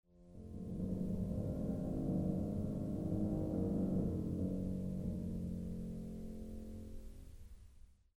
The G-flat trill (for such is the nature of the grumbling growl) portends all sorts of strange, dark and wonderful events (and not just in the first movement)
trill.mp3